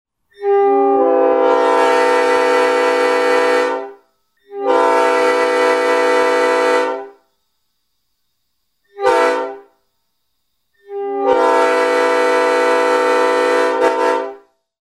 Nathan/AirChime "P Series" Locomotive Horns
P12345L (New) - static